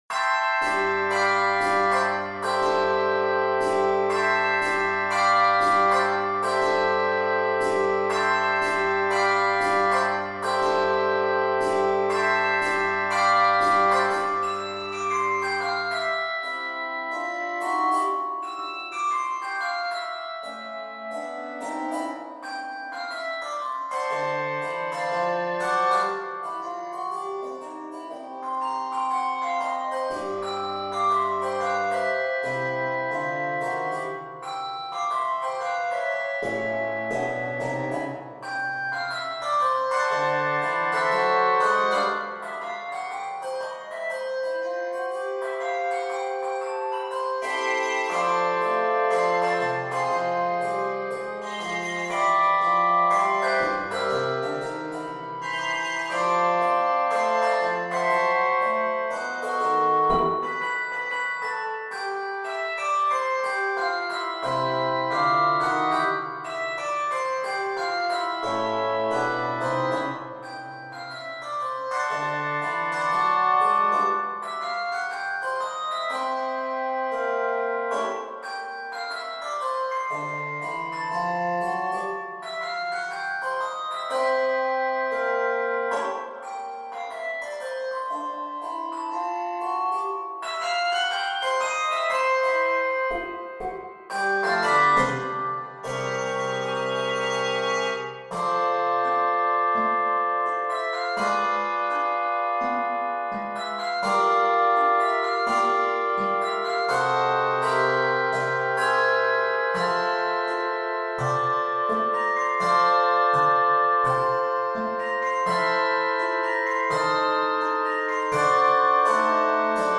The addition of handchimes tops it off!